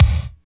28.06 KICK.wav